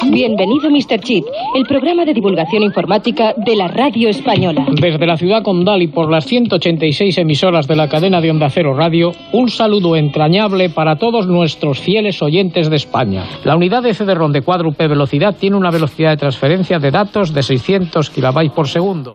Identificació, salutació i notícia sobre el CD-ROM
Divulgació